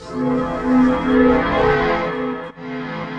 PB Chord bwd BMaj7 Effect.wav